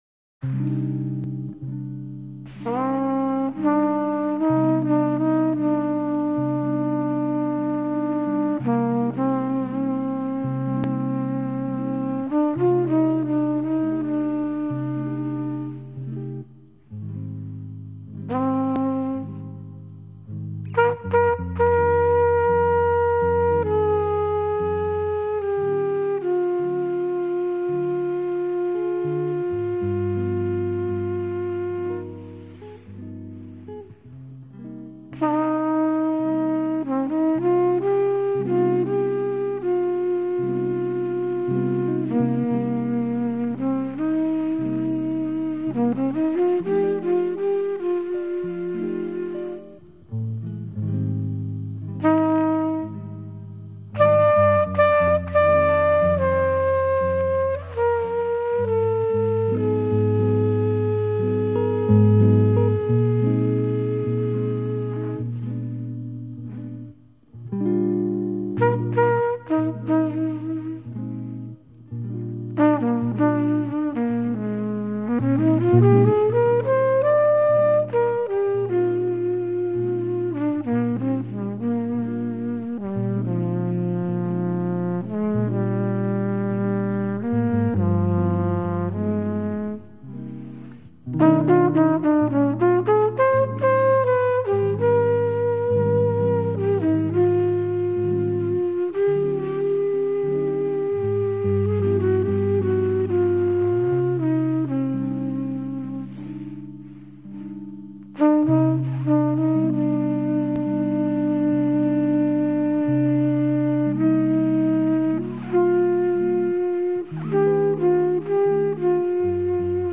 vocal,tr.
guit